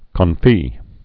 (kōn-fē, kôn-)